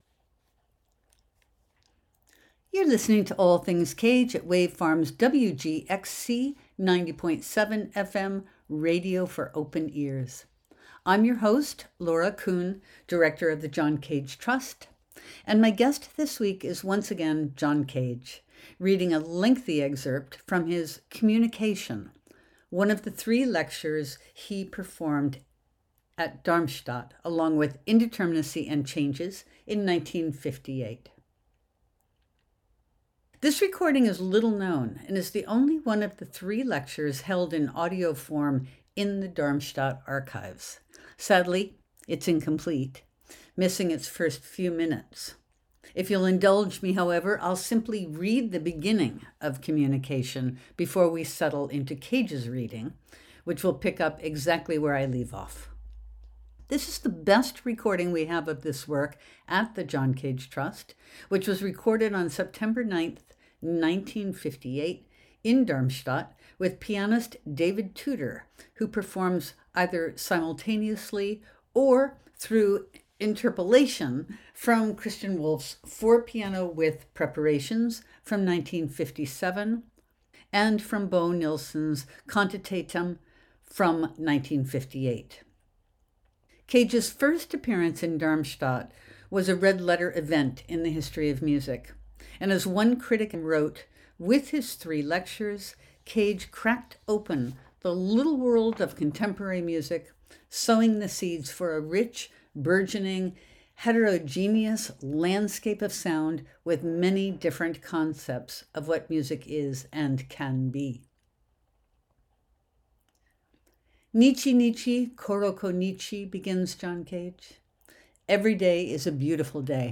Tonight on “All Things Cage” we’ll listen to John Cage reading a lengthy excerpt from his Communication , one of the three lectures he performed in Darmstadt, along with Indeterminacy and Changes , in 1958. This recording is the only one of the three lectures held in audio form in the Darmstadt archives; sadly, it is incomplete, missing its first few minutes, but I’ll simply read the beginning of Communication before we settle into Cage’s reading, which will pick up where I leave off. This is the best recording we have of this work at the John Cage Trust, which was recorded on Sept. 9, 1958, in Darmstadt, with pianist David Tudor, who performs simultaneously and in interpolations from Christian Wolff’s For Piano with Preparations (from 1957) and from Bo Nilsson’s Quantitaten (from 1958).